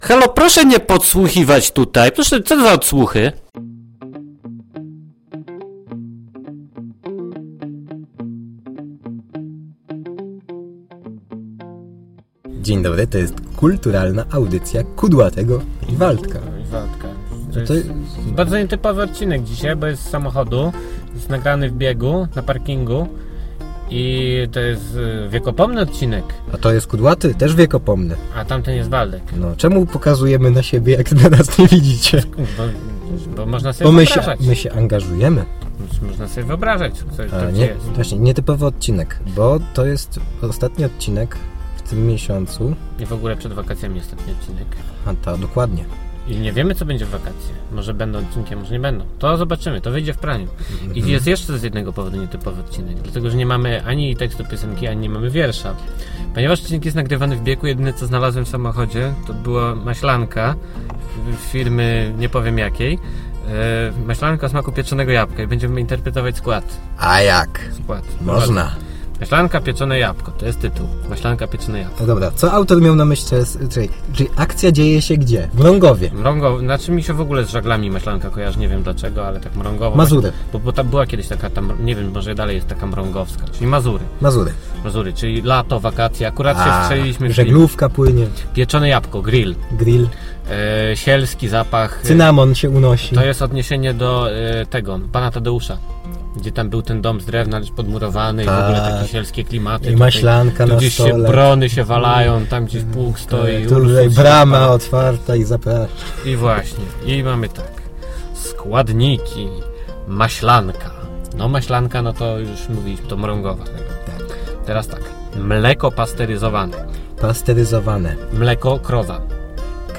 Krótki i wyjątkowy odcinek, bo z auta i bez wiersza. Interpretujemy uwaga - skład maślanki.
"Co Ałtor Miał na Myśli" to audycja rozrywkowa, nagrywana co tydzień lub dwa.